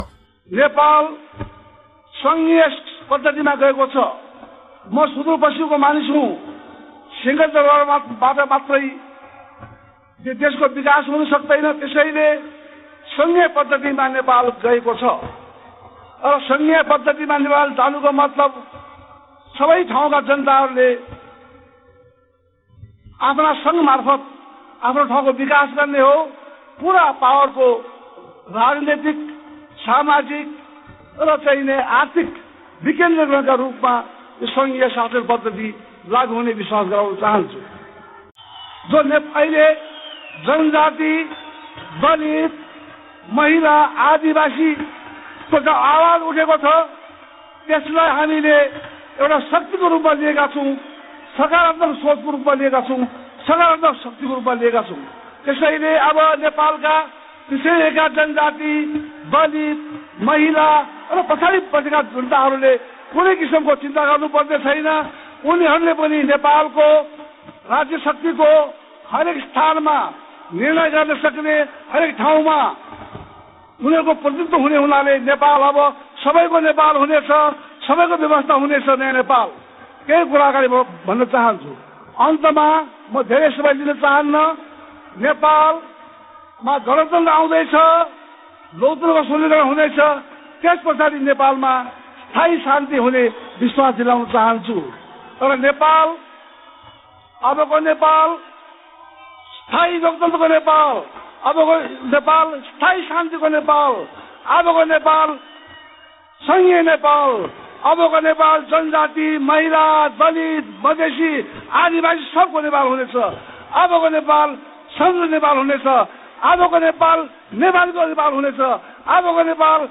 देउवा पहिले प्रष्ट बोल्थे (अडियो सहित)
२०६५ साल काठमाण्डौको खुलामंचमा गरेको भाषणमा उनको बोली प्रष्ट छ । पुरानो भाषणहरुमा, देउवाका आवज प्रष्ट बुझ्न र सुन्न सकिन्छ ।
खुलामंचमा देउवाको भाषण,२०६५ (अडियो )